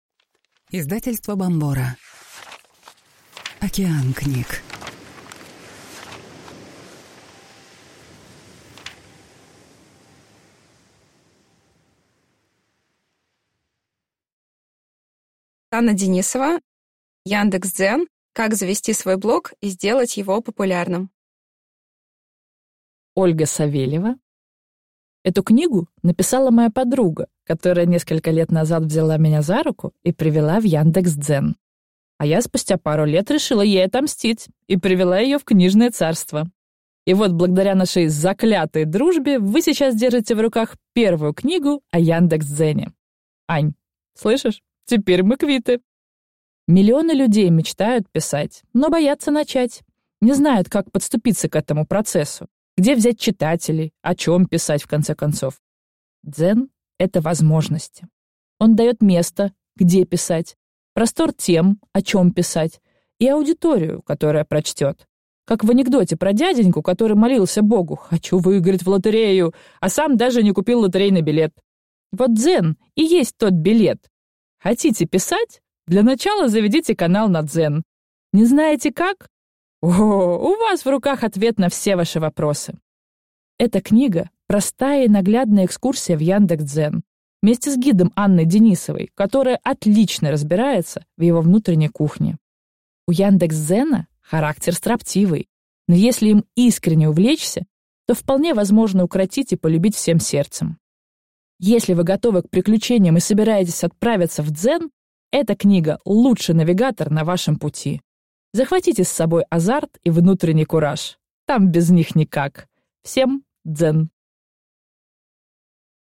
Аудиокнига Яндекс.Дзен. Как создать свой блог и сделать его популярным | Библиотека аудиокниг